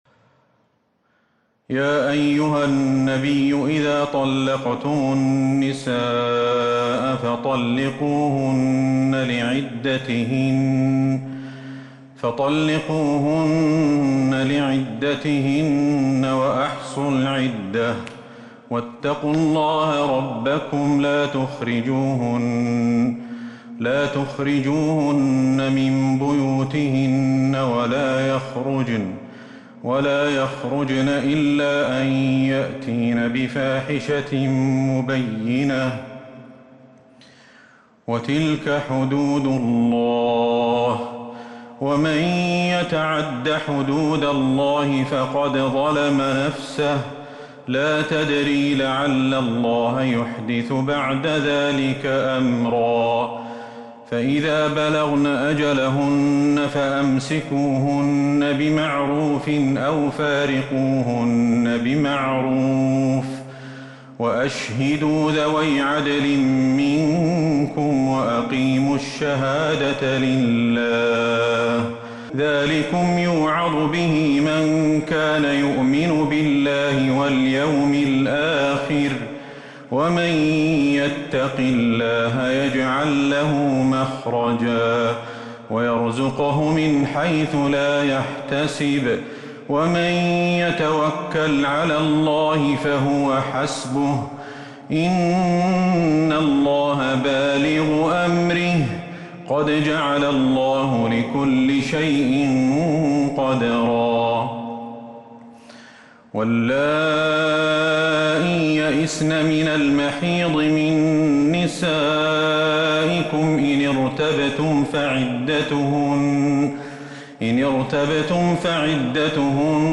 سورة الطلاق Surat At-Talaq من تراويح المسجد النبوي 1442هـ > مصحف تراويح الحرم النبوي عام ١٤٤٢ > المصحف - تلاوات الحرمين